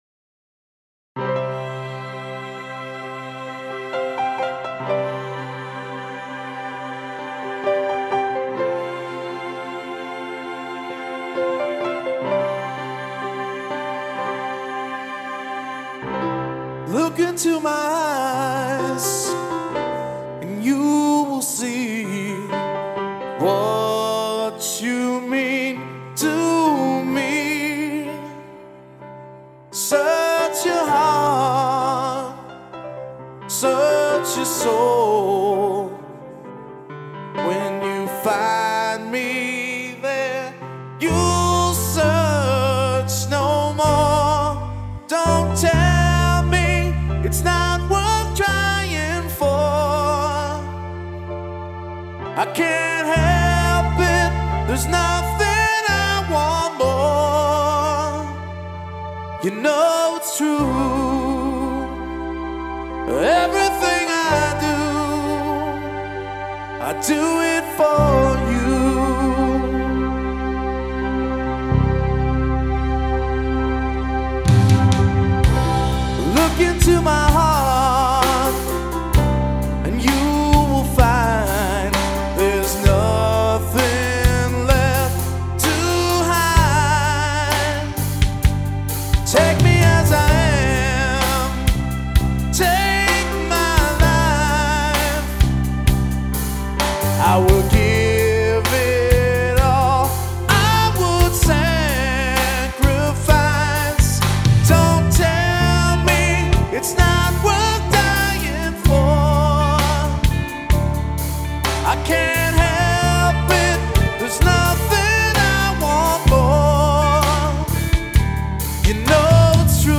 Vocals
Not thrilled with the strings though but they are alright.
Wow, what a voice.